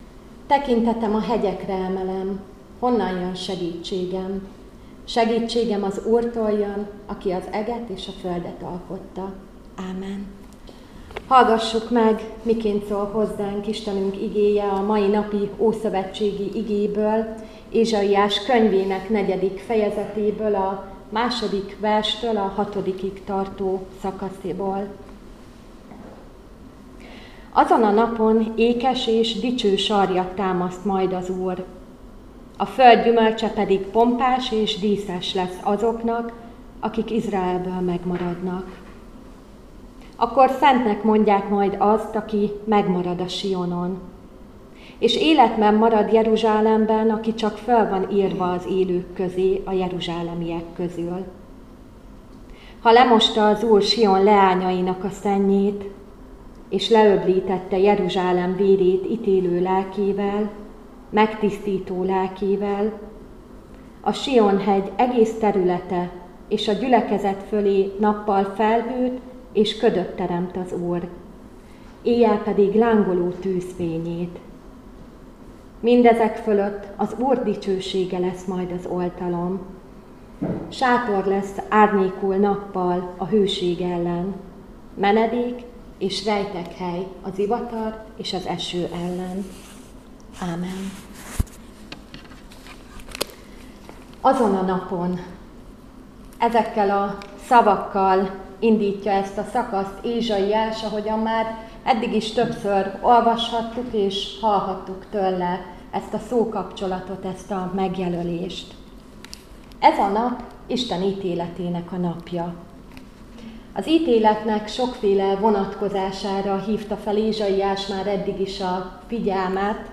Áhítat, 2025. október 14.